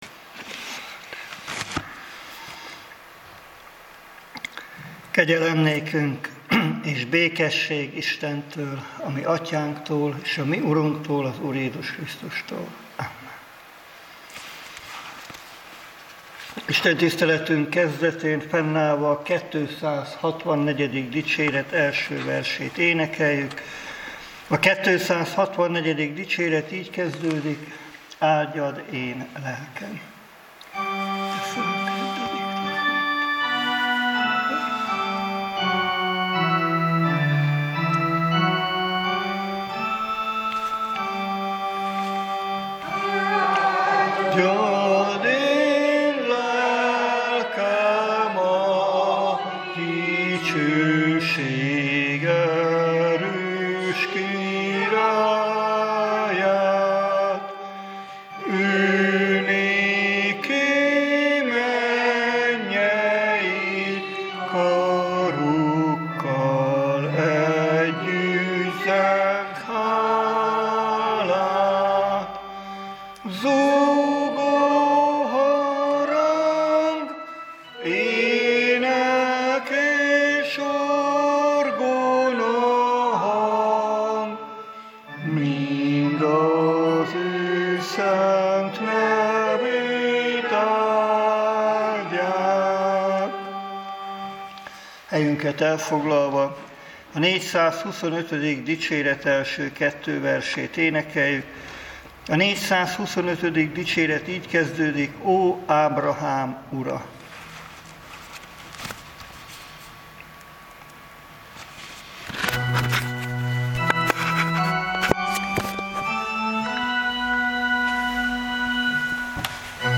2023. június 6. istentisztelet, MP3